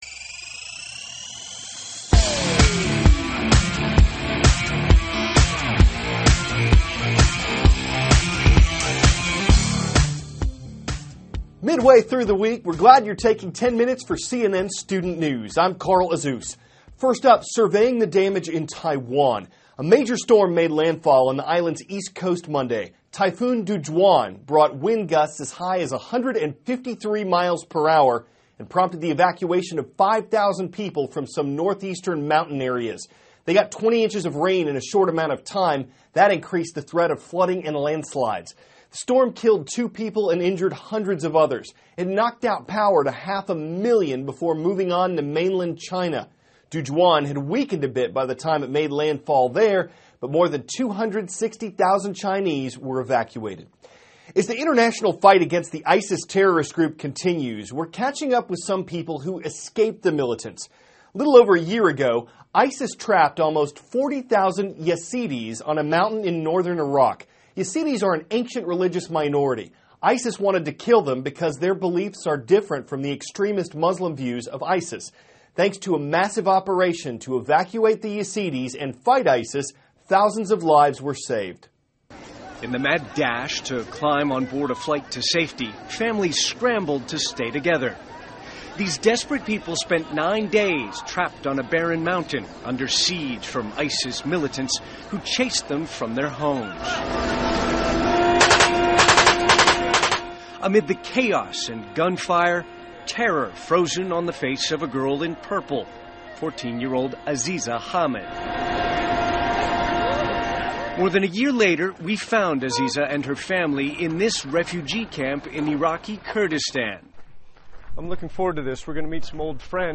CARL AZUZ, cnn STUDENT NEWS ANCHOR: Midway through the week, we`re glad you`re taking 10 minutes for cnn STUDENT NEWS.